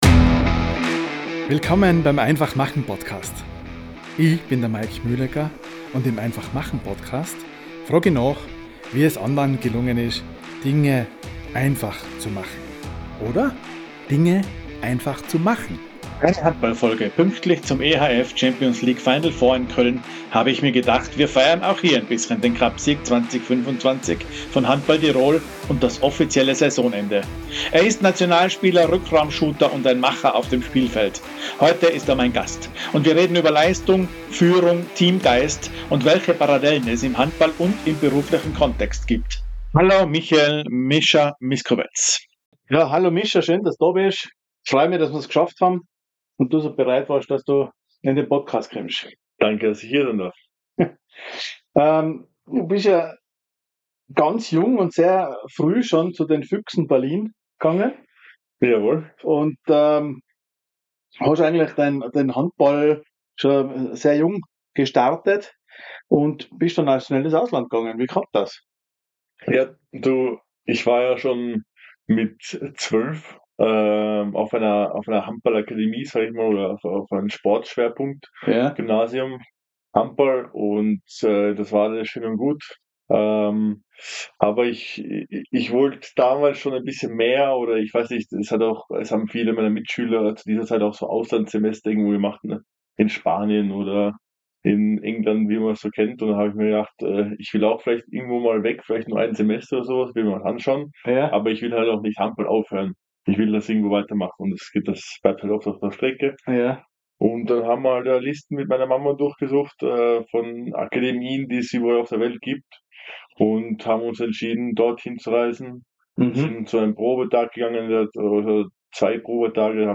Im neuen einfach.machen Podcast reden wir über: Was Leistung wirklich braucht Wie man mit Rückschlägen umgeht Warum Mut nicht immer laut sein muss Ein Gespräch über Handball – aber eigentlich über das Leben.